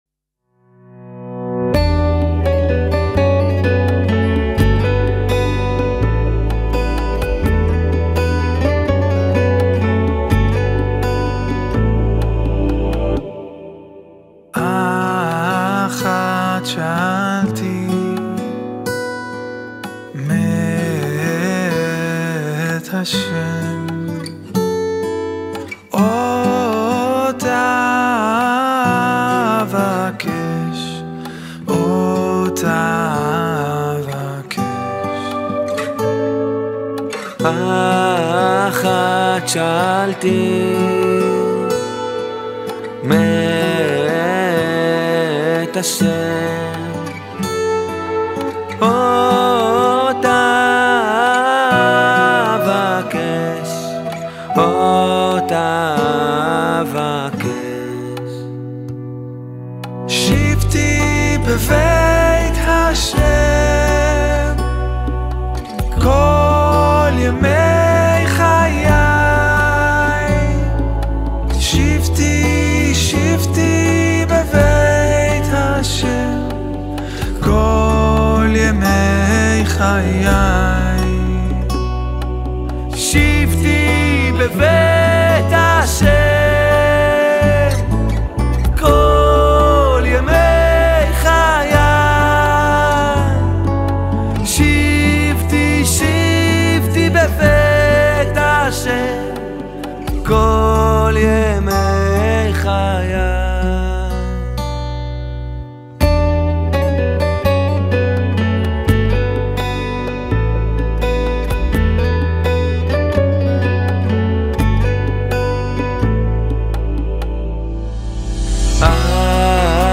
תופים
גיטרות, בס, קלידים ותכנותים